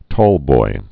(tôlboi)